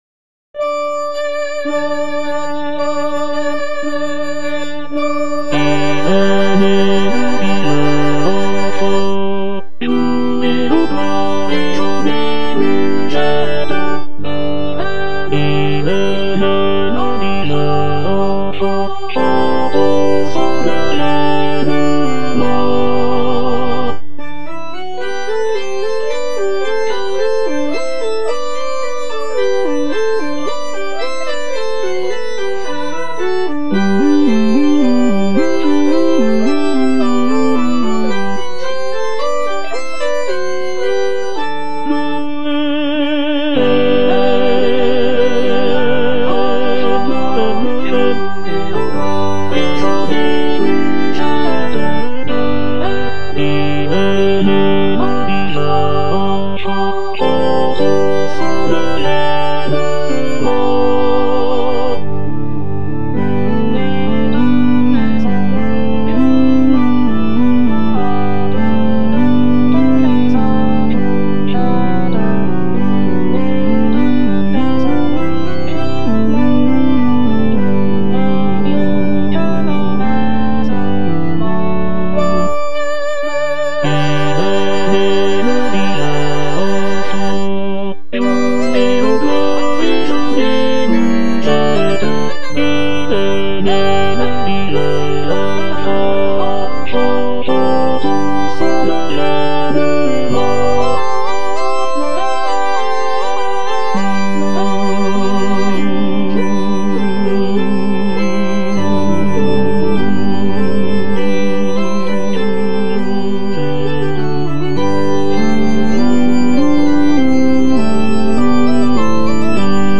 Tenor II (Emphasised voice and other voices)
traditional French Christmas carol